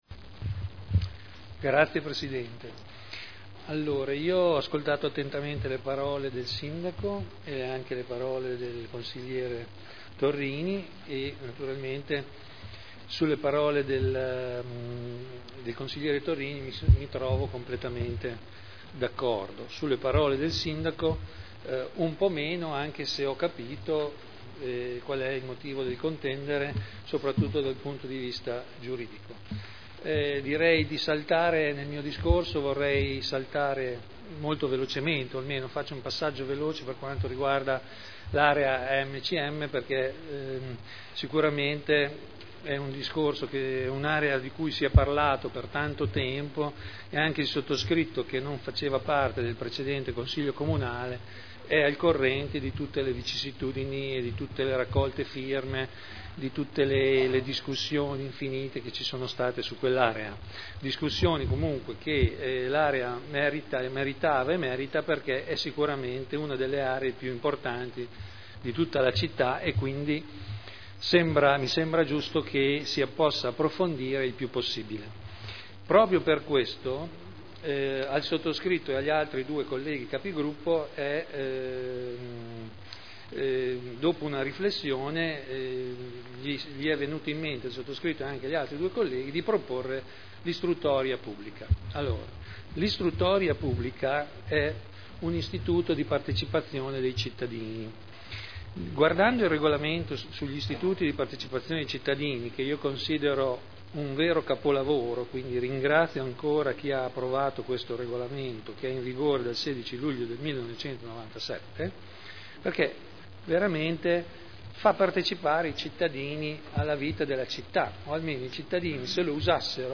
Seduta del 14/12/2009. dichiarazioni di voto